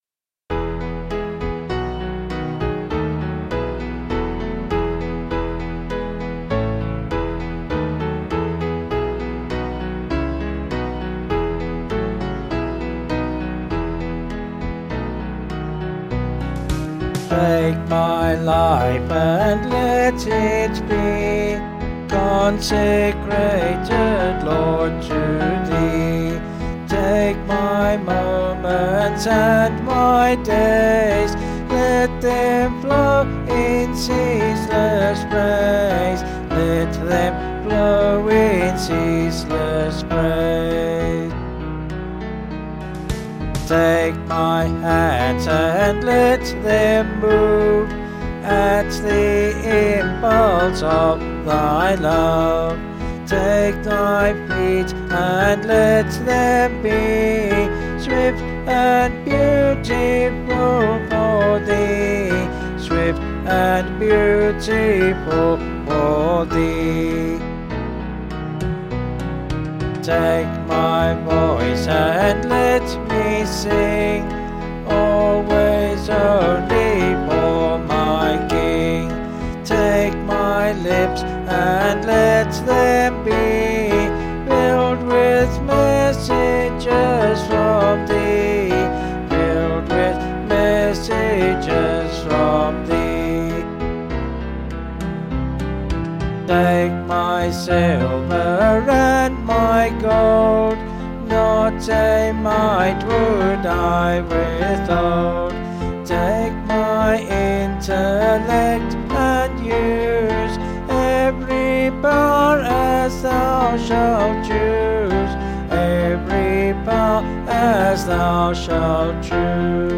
Vocals and Band   264.9kb Sung Lyrics